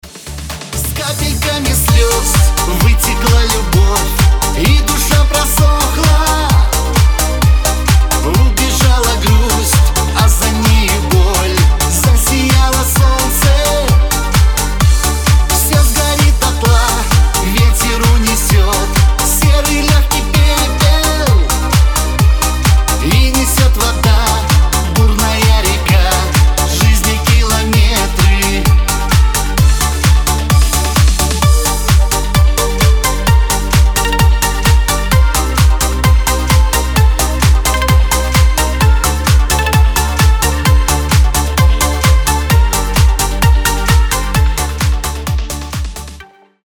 • Качество: 320, Stereo
мужской голос
воодушевляющие